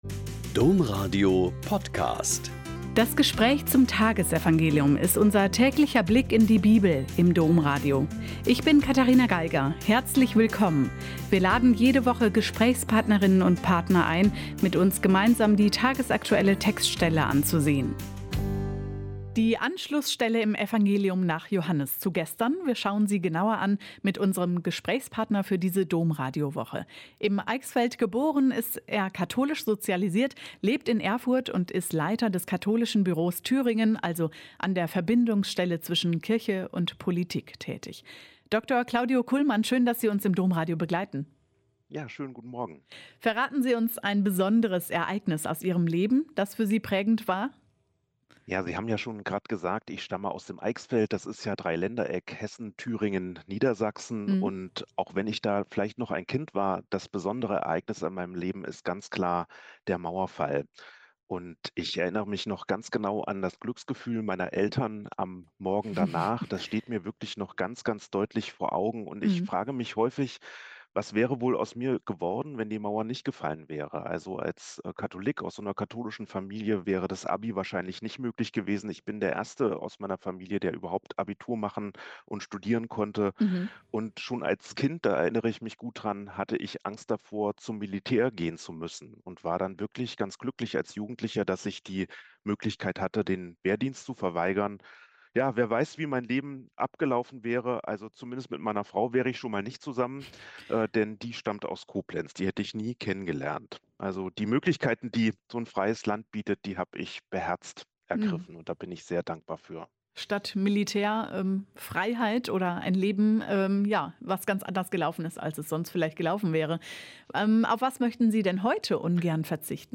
Joh 6,30-35 - Gespräch